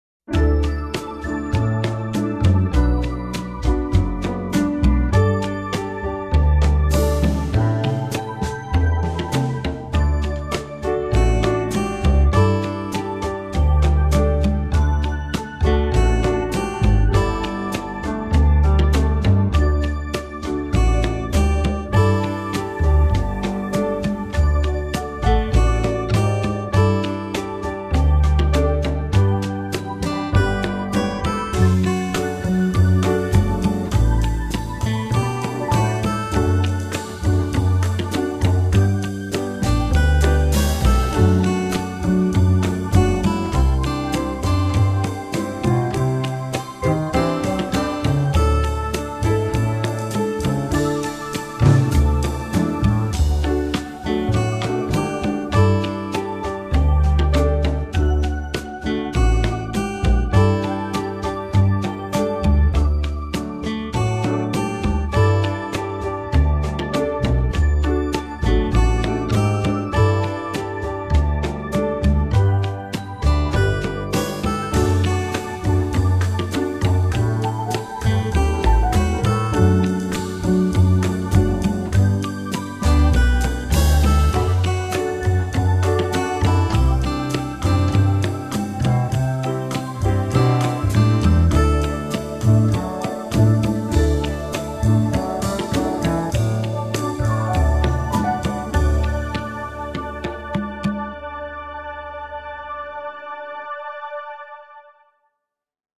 I had fun with this laid back backing: